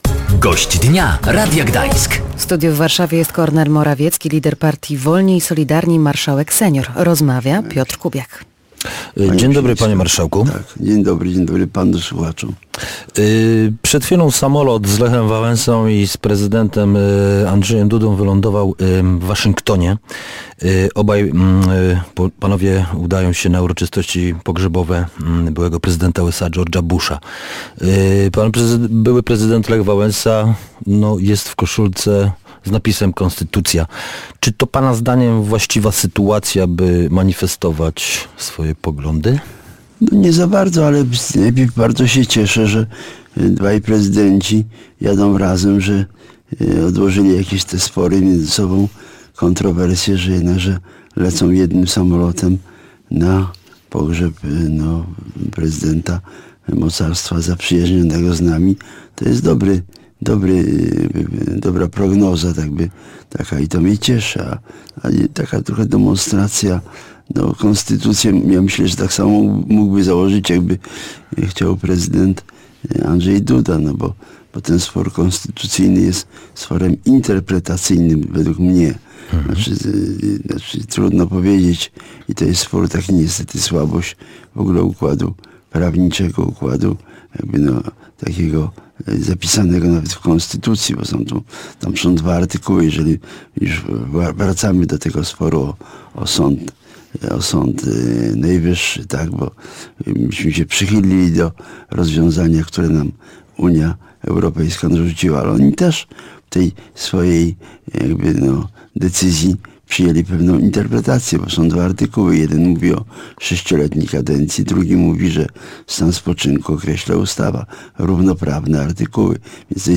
Samolot z Lechem Wałęsą i prezydentem Andrzejem Dudą wylądował już w Waszyngtonie. Obaj prezydenci udają się na uroczystości pogrzebowe byłego prezydenta USA George’a H.W. Busha. Gościem Radia Gdańsk był Kornel Morawiecki, lider partii Wolni i Solidarni, marszałek senior.